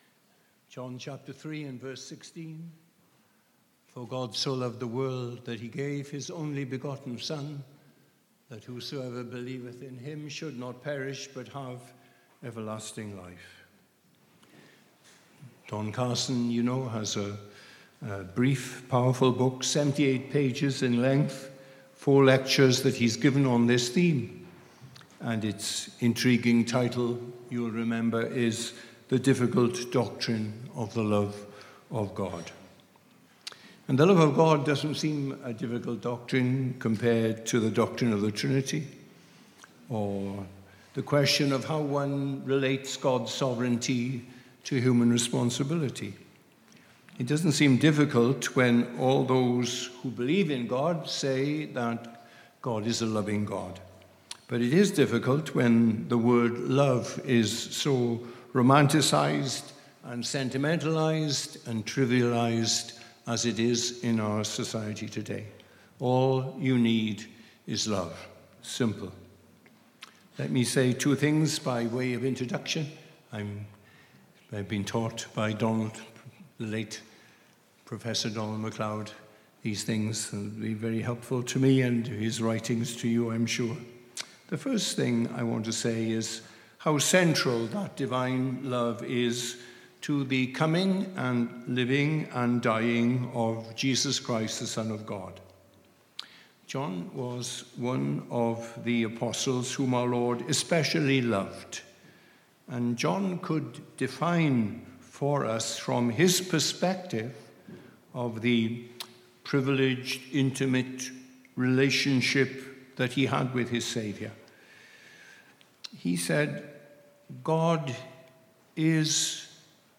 Worship Service 2 – The Breadth of the Love